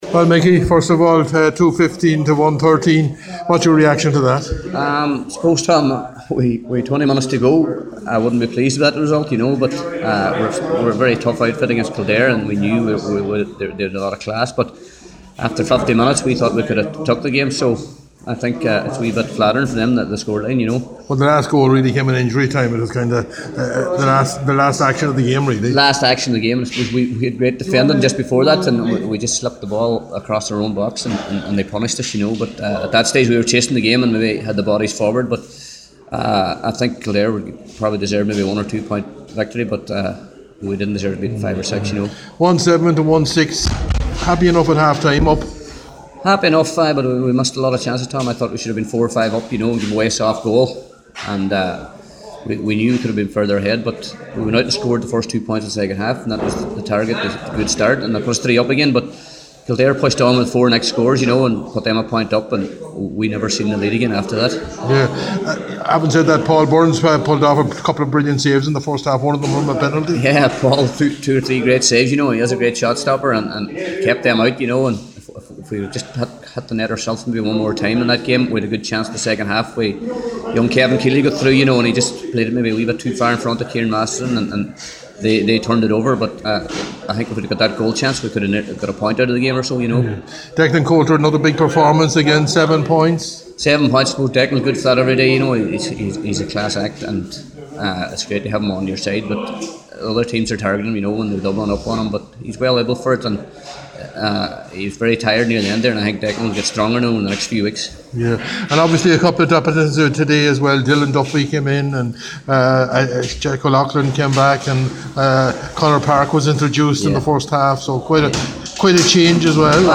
After the match